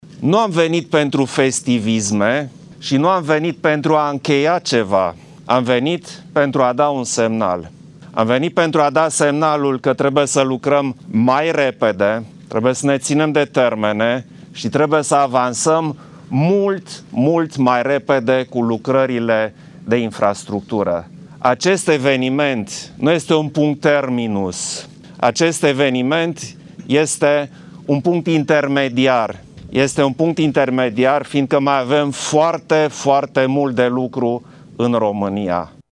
Șeful statului a făcut această declarație la deschiderea tronsonului de autostradă Iernut – Cheţani din judeţul Mureş subliniind că această paradigmă se va schimba și că acum se vrea.
Președintele a ținut să precizeze că nu din motive de festivism a ținut să fie prezent la inaugurarea celor 19 km de autostradă: